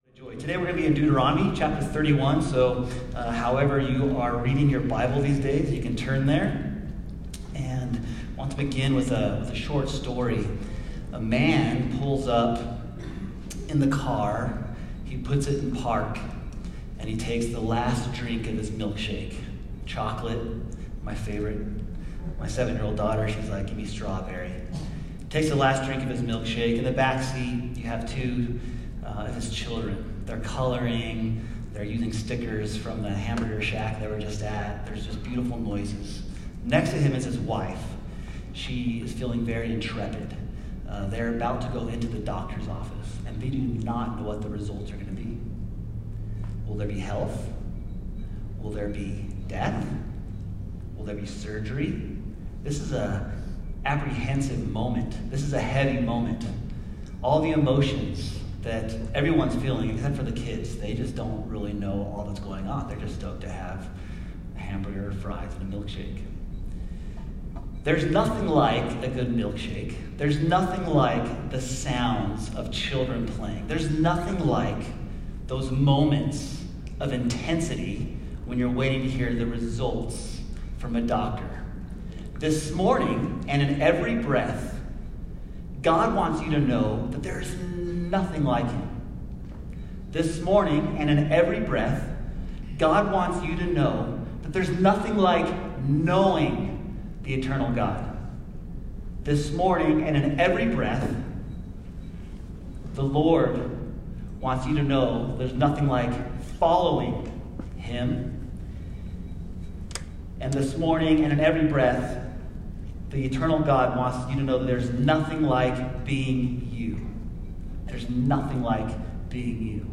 Preaching: There’s Nothing like ____________.